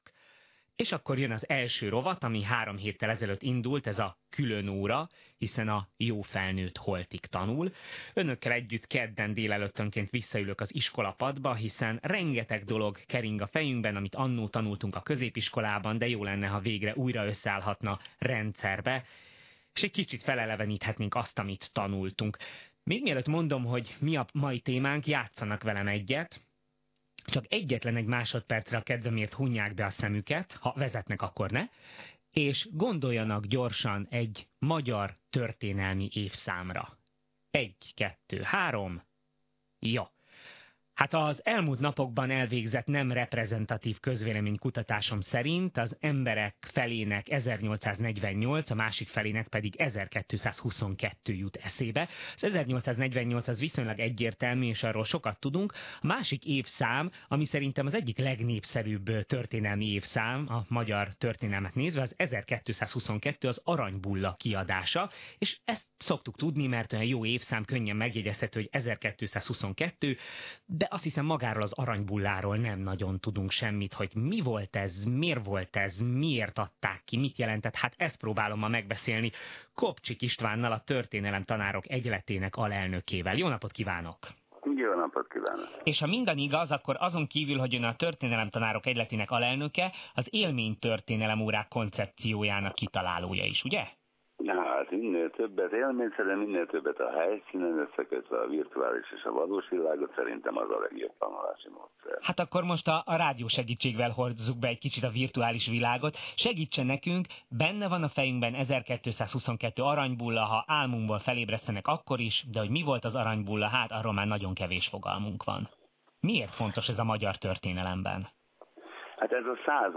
Az interjú itt meghallgatható és letölthető